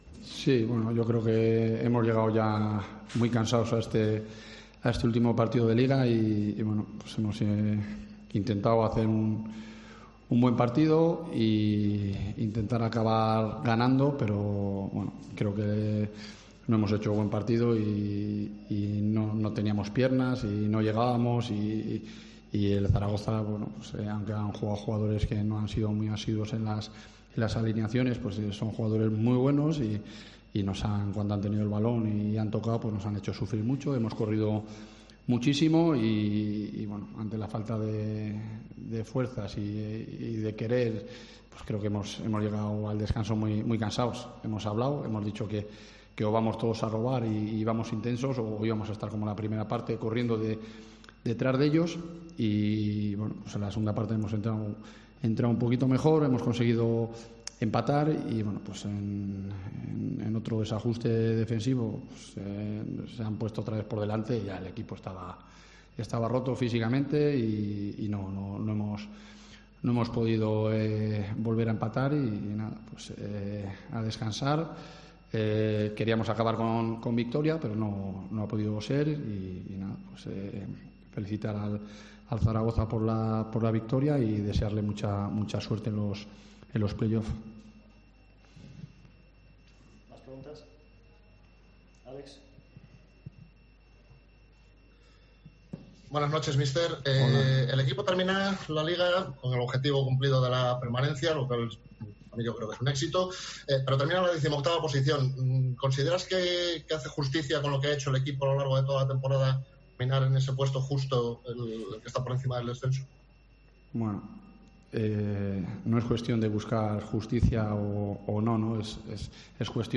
POSTPARTIDO SEGUNDA A J.42